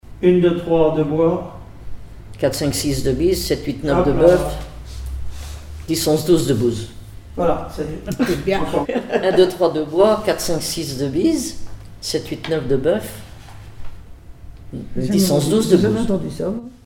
Mémoires et Patrimoines vivants - RaddO est une base de données d'archives iconographiques et sonores.
Enfantines - rondes et jeux
formulette enfantine : amusette
Pièce musicale inédite